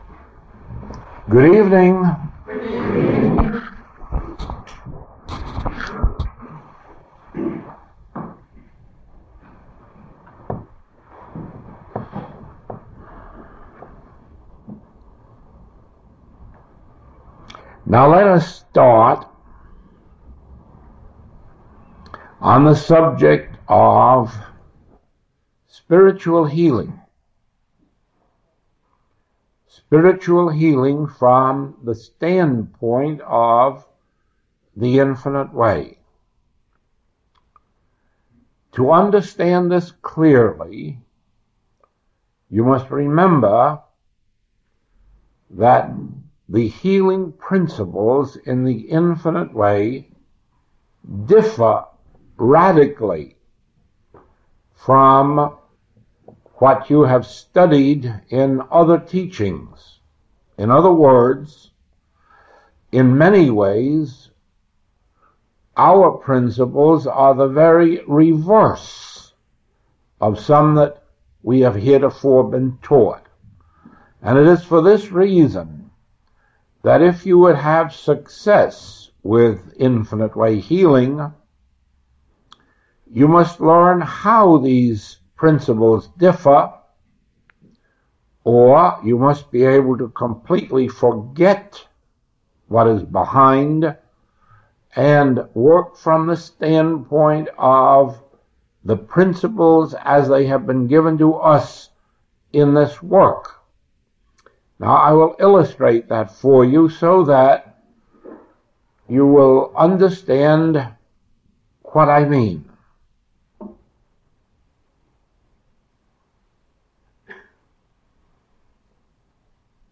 Recording 498A is from the 1962 Stockholm Closed Class.